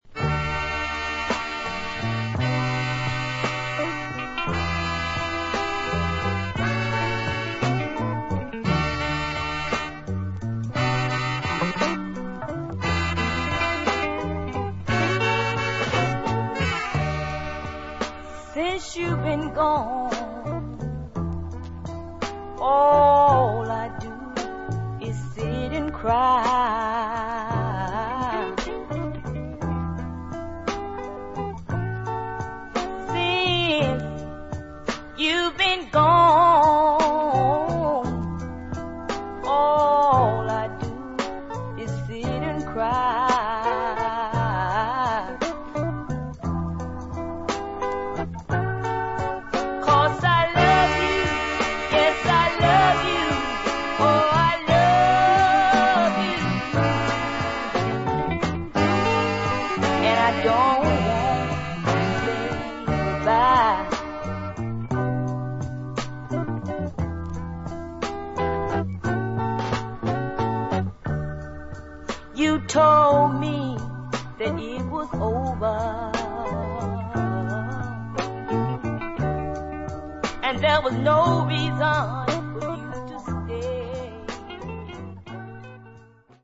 Brilliant 70's/Southern Soul dancer
great Deep Soul side!